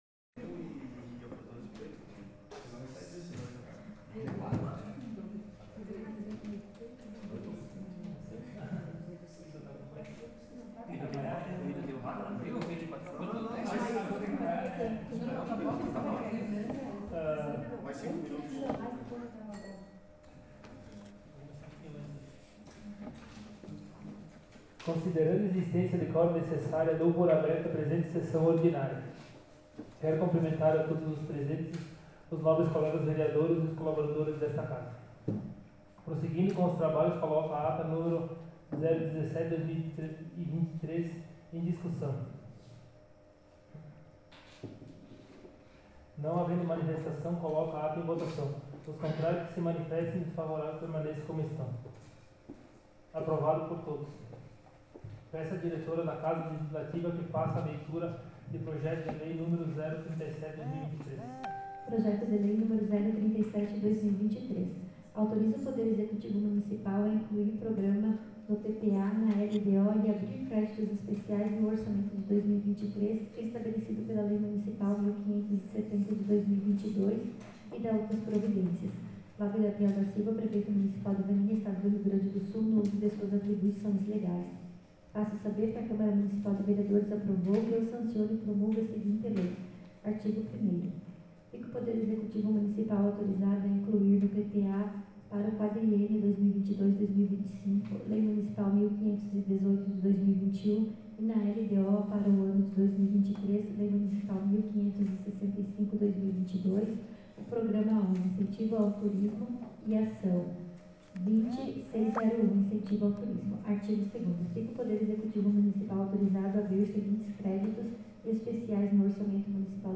Em anexo arquivo de gravação em áudio da Sessão Ordinária realizada na Câmara de Vereadores de Vanini na data de 24/10/2023.
Gravação em áudio da Sessão Ordinária do dia 24 de Outubro de 2023